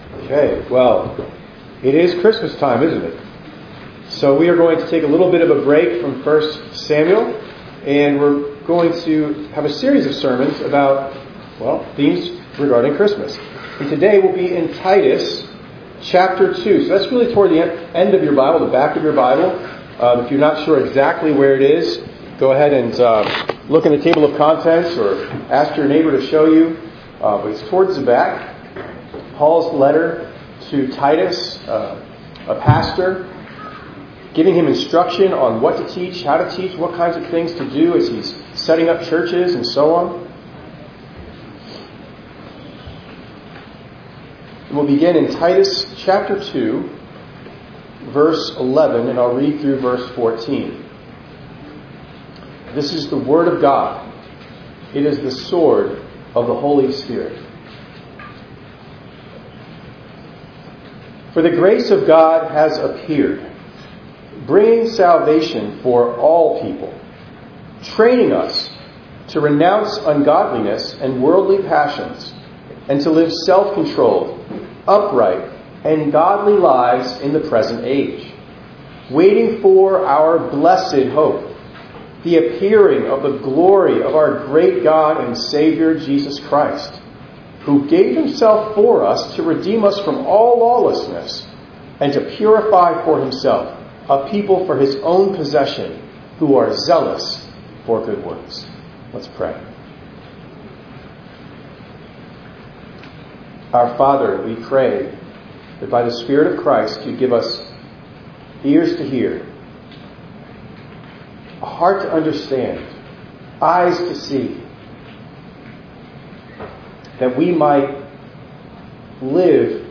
12_1_24_ENG_Sermon.mp3